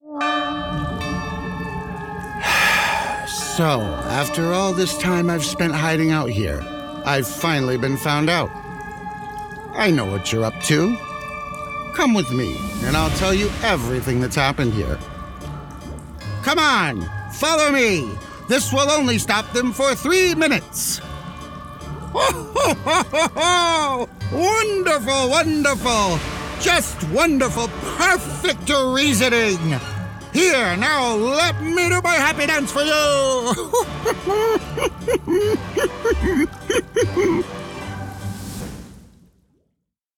ANIMATION
Mad Scientist Lab Frenzy Animated Voiceover Demo
Mad-Scientist-Lab-Frenzy-Animated-Voiceover-Demo.mp3